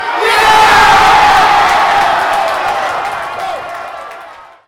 applause-0aaec1e9.mp3